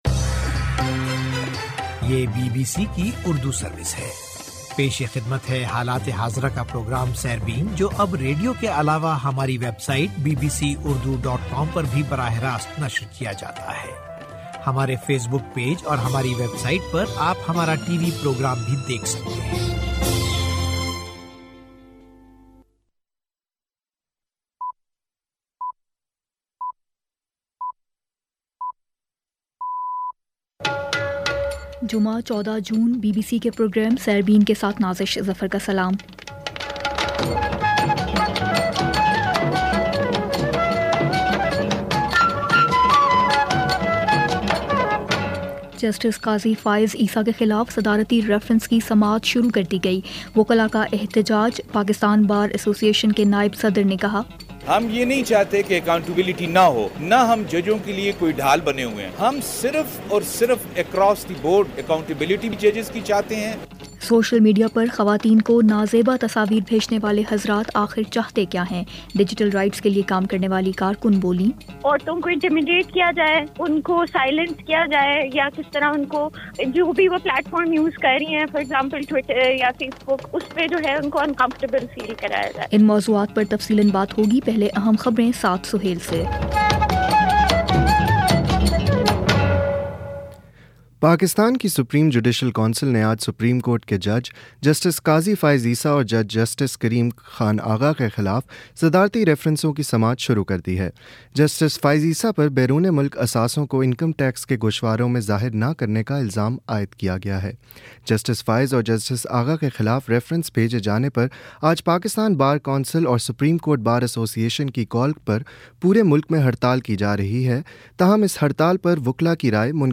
جمعہ 14 جون کا سیربین ریڈیو پروگرام